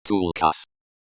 Presumably named for The Silmarillion character Tulkas, pronounced in Quenya as /
ˈtul.käs/.